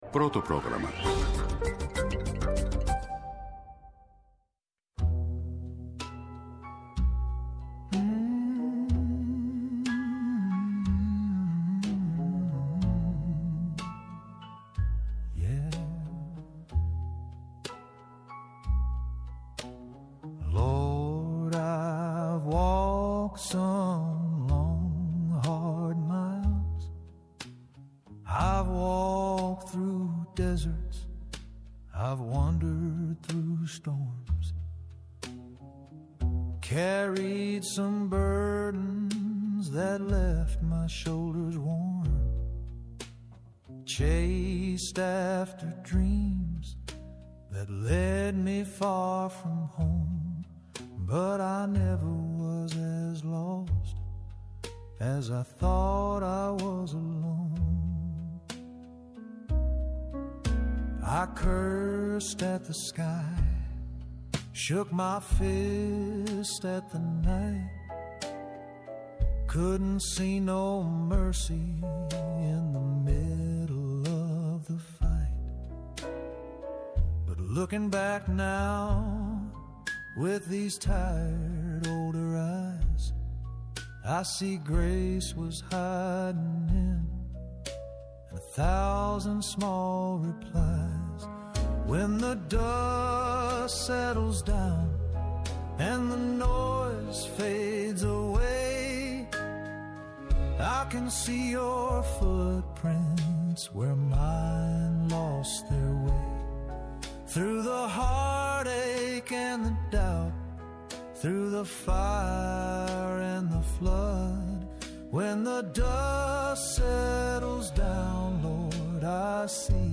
-Ο δημοσιογράφος Τάσος Τέλλογλου, για τον σχολιασμό της επικαιρότητας,